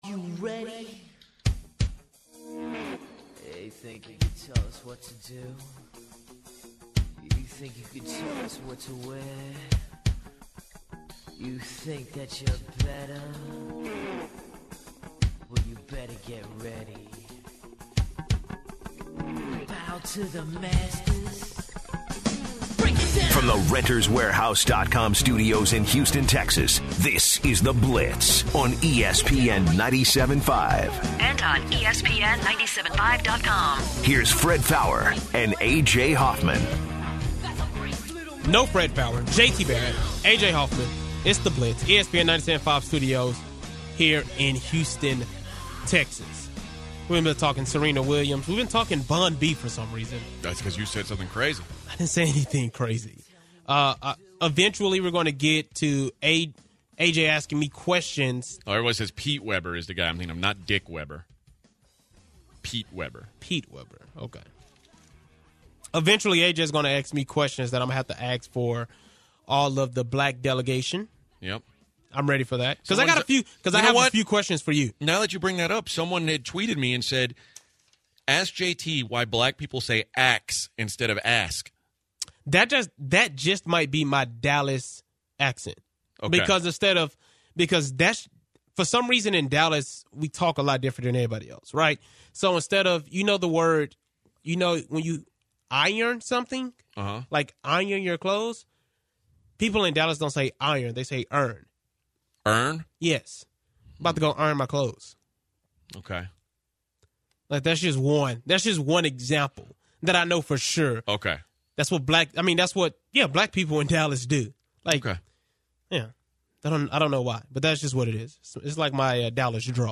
IN HOUR TWO THE GUYS ARE SWARMED WITH CALLS ABOUT THEIR MUSIC TOPIC. ALSO A LOOK ON THE GUYS VIEW OF RACE IN SPORTS